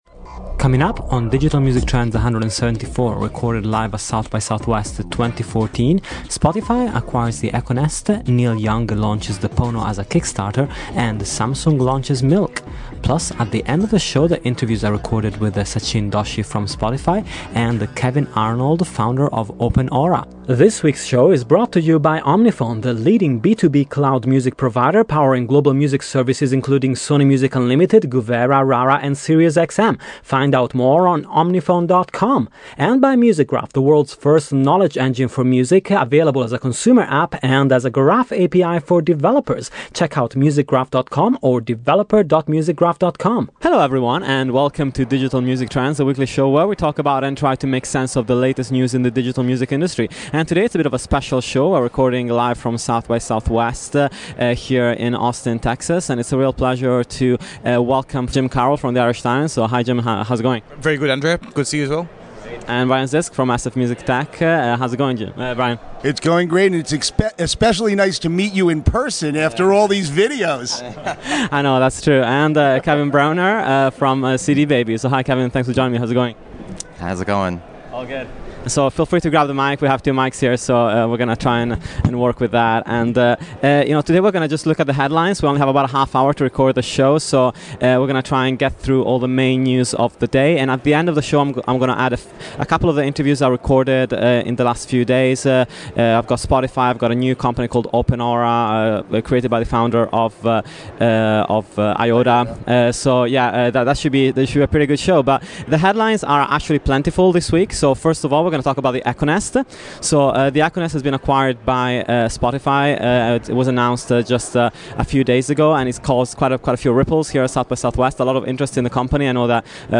A live show!